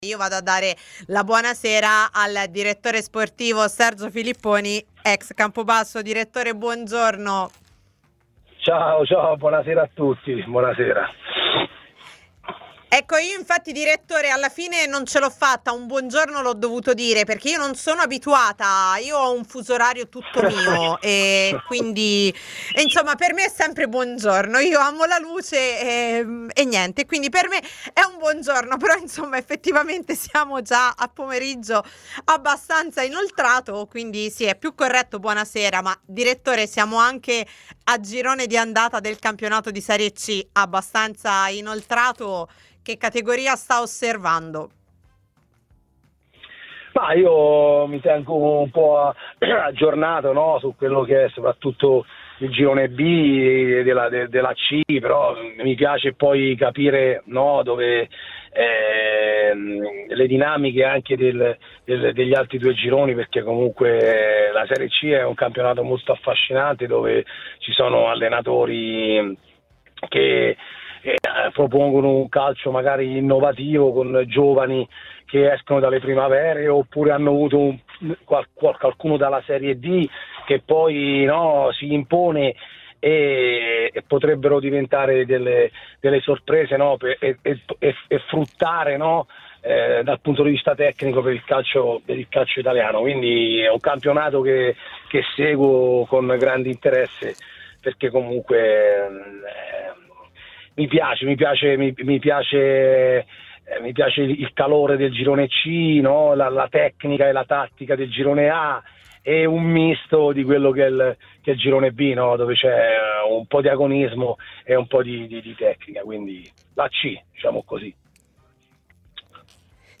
ai microfoni di TMW Radio